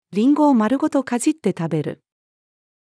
リンゴを丸ごと齧って食べる。-female.mp3